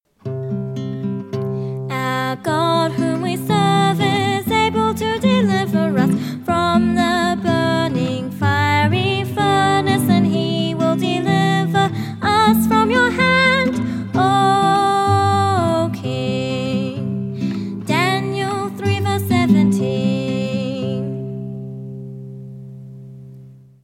Soloist
Guitar